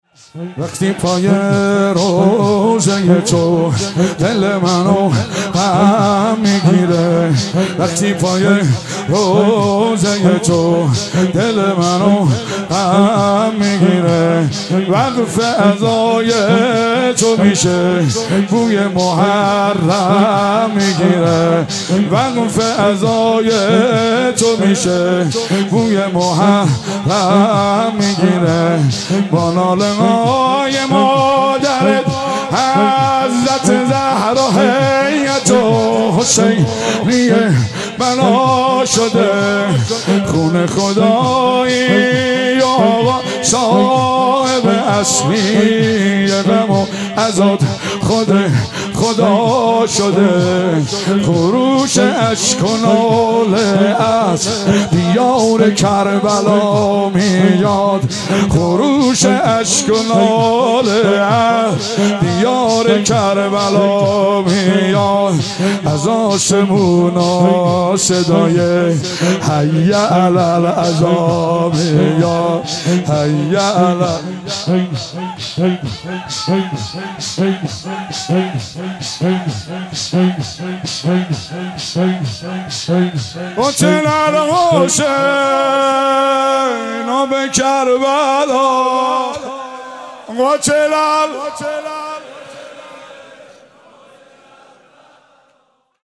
روضه پایانی شب عاشورا